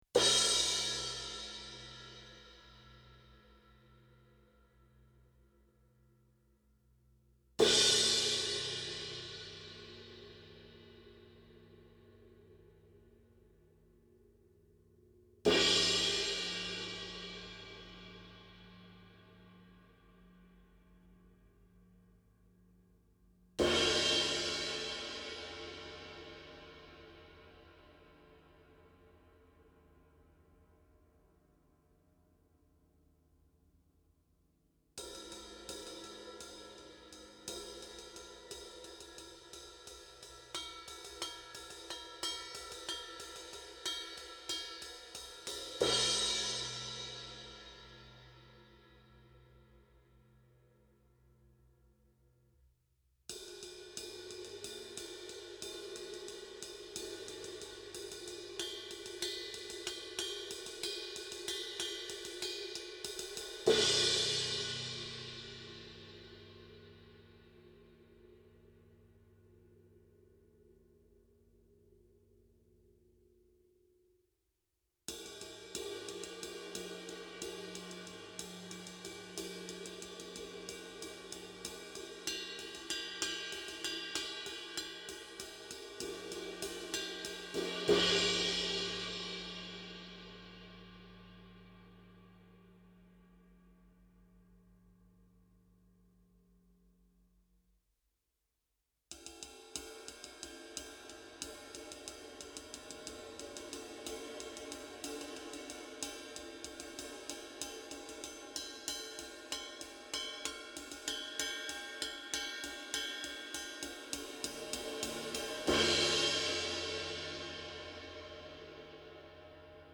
Laitoin tuossa muutama p�iv� sitten pellit riviin ja ��nitin pienen testivertailun (18" vs. 20" vs. 21" vs. 22"), jos jotakuta sattuisi kiinnostamaan: http
My�s hiljattain taloon saapunut Sakae Trilogy 14x5,5" virveli on kuultavissa t�sss� p�tk�ss�.
Jokainen pelti toimii tarvittaessa joko crashina tai riden� riippuen siit�, millaista s�velkorkeutta ja sustainia onkaan vailla. Ilahduttavan monipuolisia, herkki� ja rikassoundisia l�ttyj�.